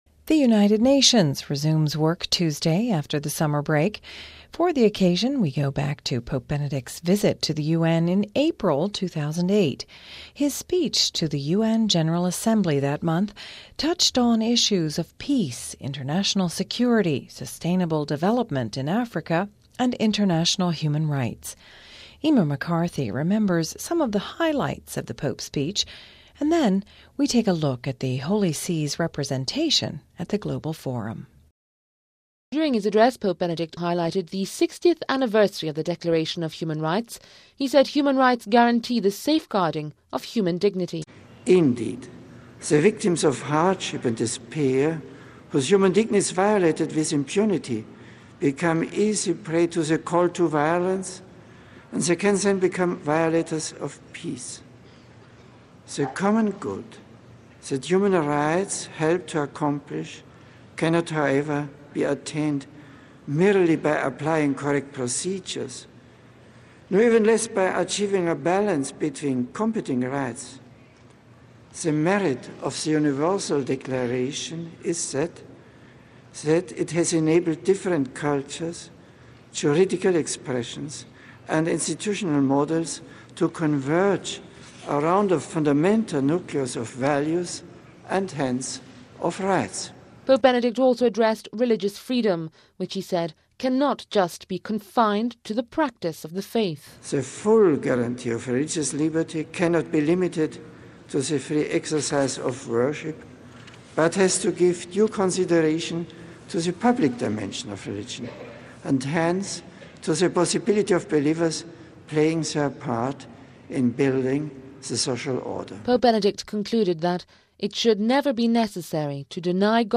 We focus on the United Nations as it begins its working session with highlights from Pope Benedict’s 2008 speech to the General Assembly in New York – plus, an overview of how the Holy See is represented at the world body...